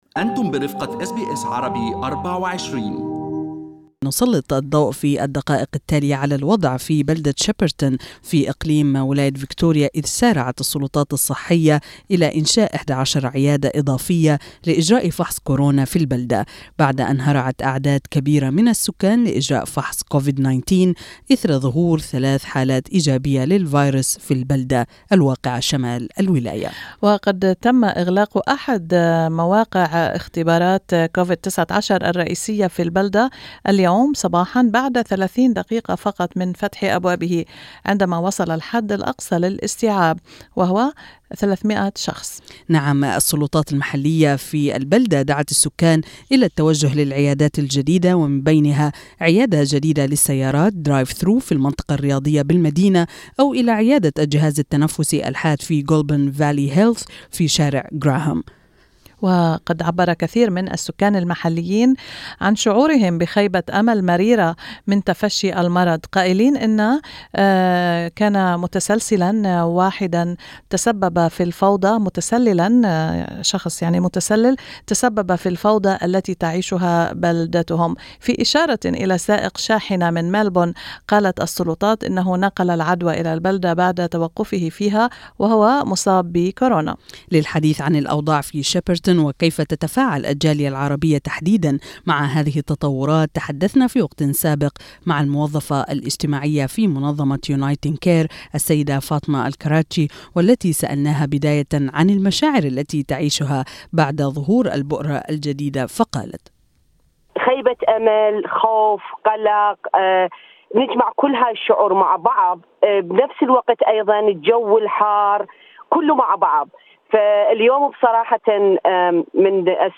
سيدة عربية في شيبرتون: نشعر بالقلق والخوف من ظهور بؤرة جديدة لكورونا في البلدة